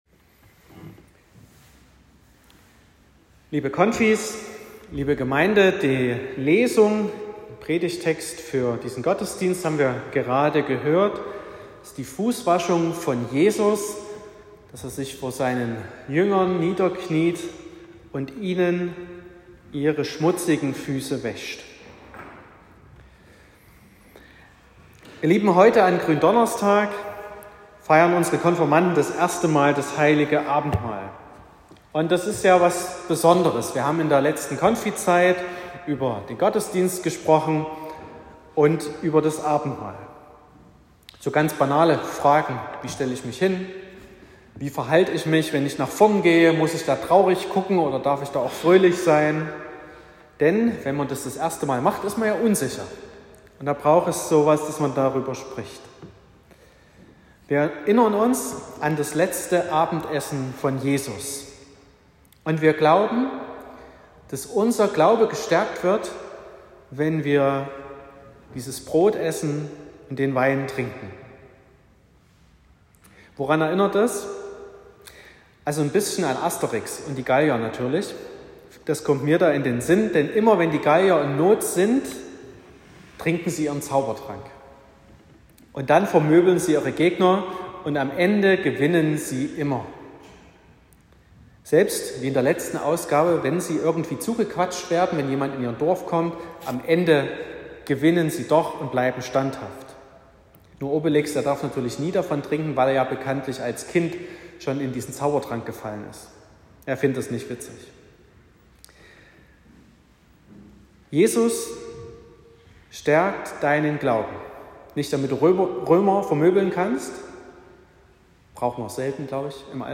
28.03.2024 – Gottesdienst mit Erstabendmahl der Konfirmanden Klasse 7
Predigt (Audio): 2024-03-28_Kniende_Liebe.m4a (7,2 MB)